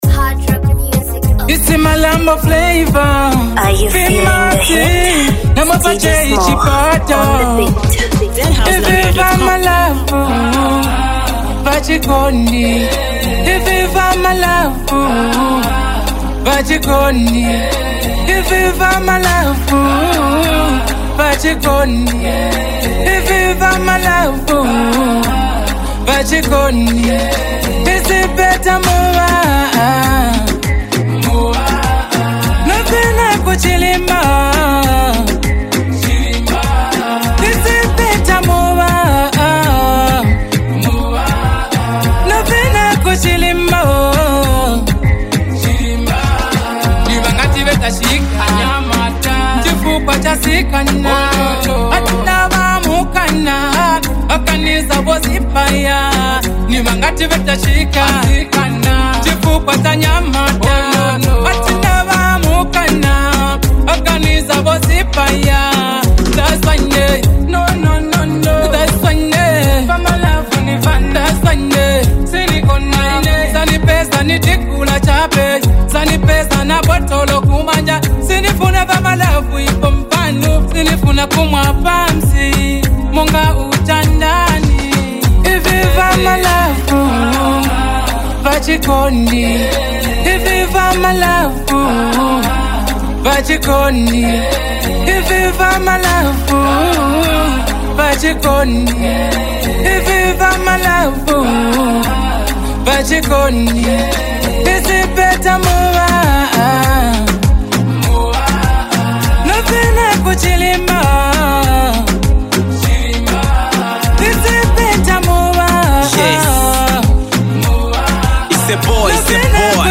a vibey street anthem